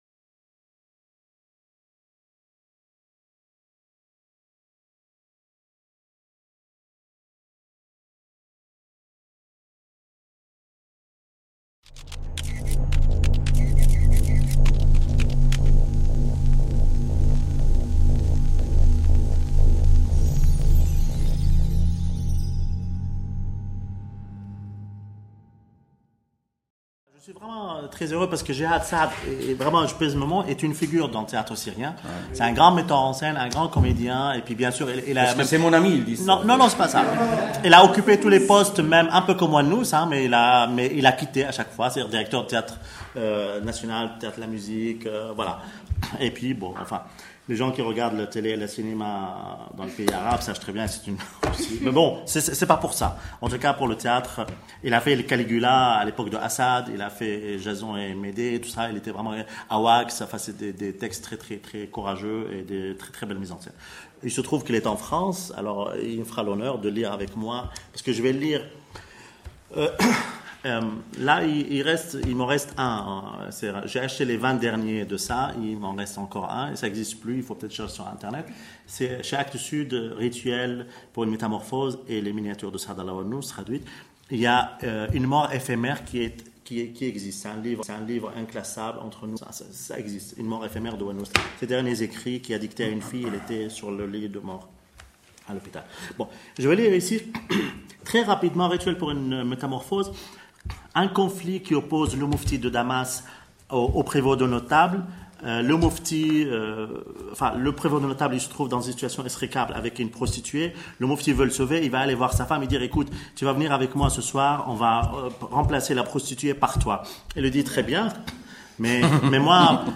Table Ronde Avec